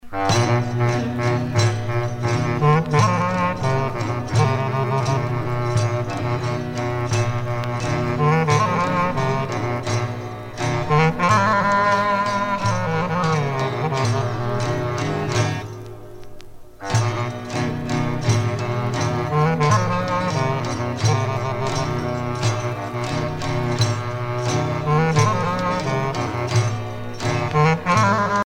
danse : syrtos (Grèce)
Pièce musicale éditée